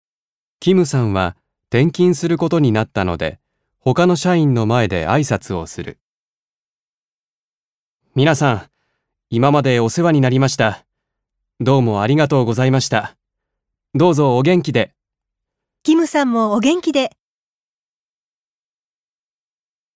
1.2. 会話（異動いどう場面ばめんでの挨拶あいさつ